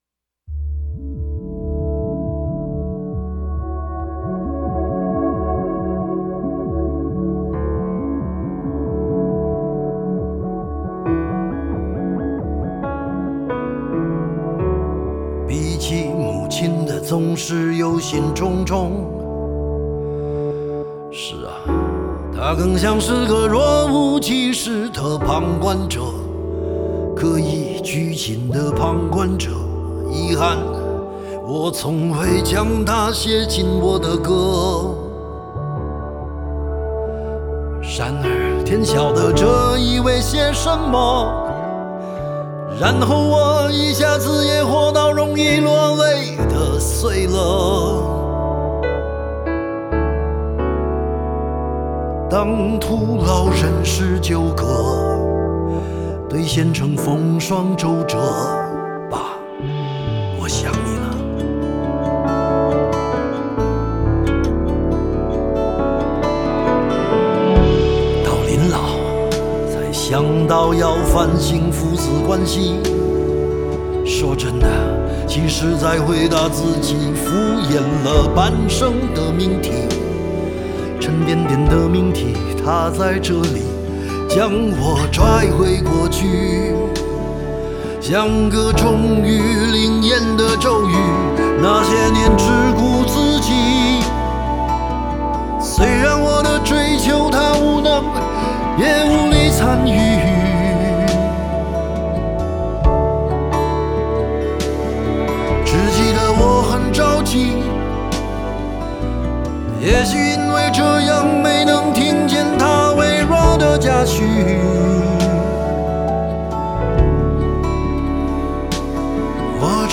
钢琴/电钢琴/Keyboards
鼓
贝斯Bass
吉他
弦乐